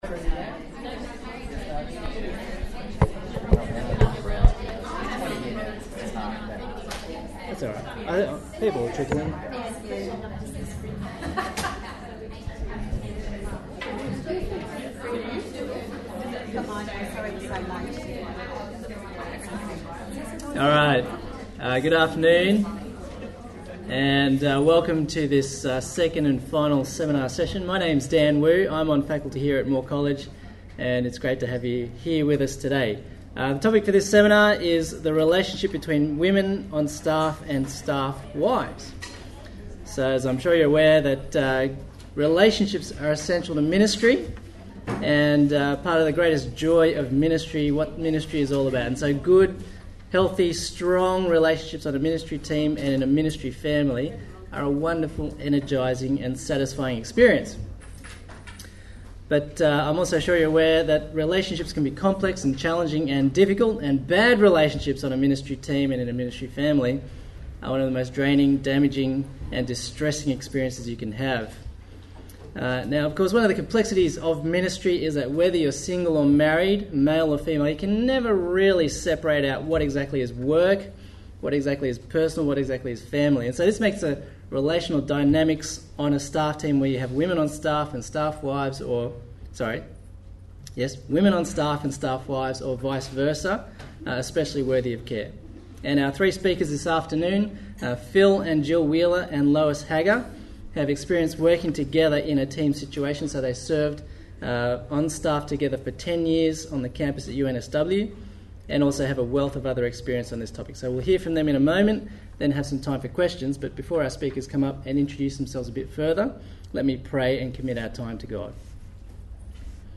Elective at the 2014 Priscilla & Aquila Centre conference.